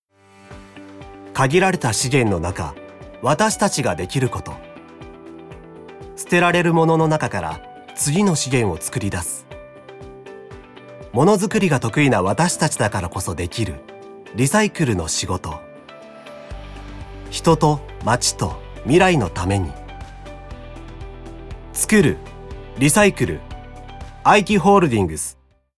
ナレーション
ボイスサンプル
1.プロテインのCM。かっこよく。(2024)
2.映画紹介のCM。テンション高く。
3.住宅CM「ただいま」(優しく)
4.住宅系のCM。爽やかに。
6.企業CM「リサイクル編」ストレート。